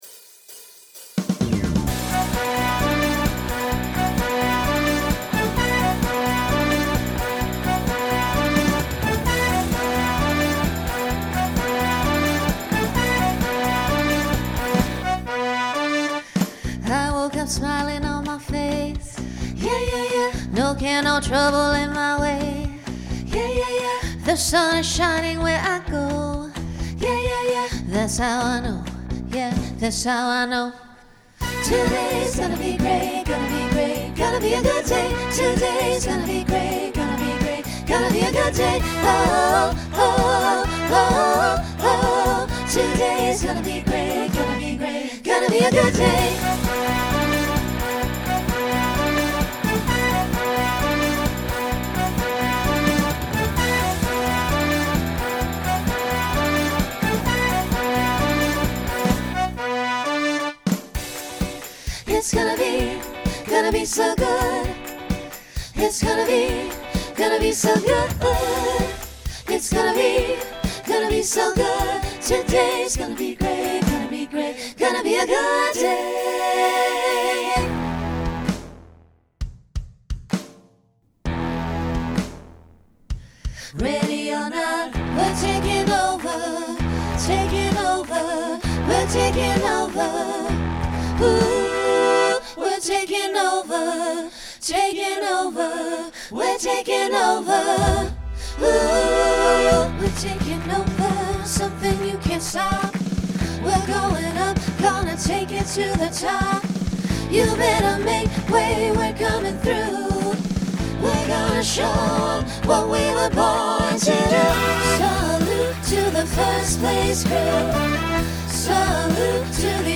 Utilizes an SATB quartet to facilitate a costume change.
Genre Pop/Dance , Rock
Transition Voicing SATB